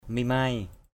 /mi-maɪ/ mimai m{=m [Bkt.]